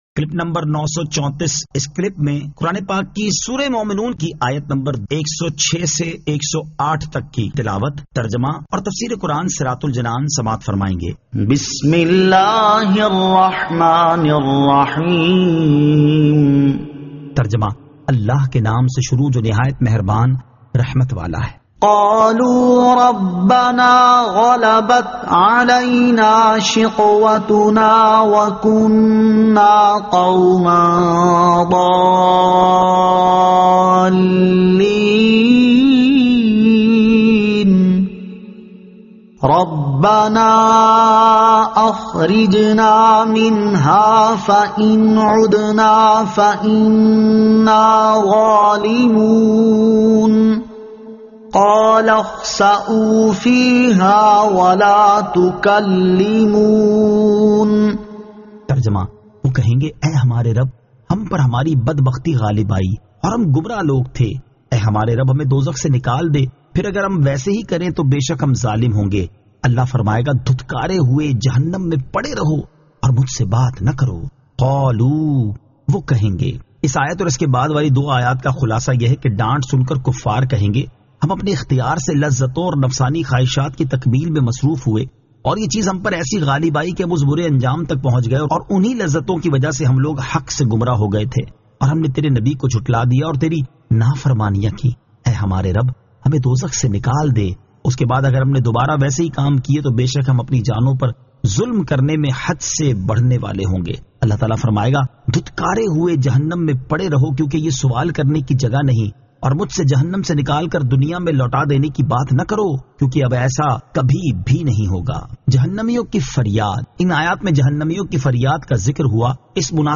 Surah Al-Mu'minun 106 To 108 Tilawat , Tarjama , Tafseer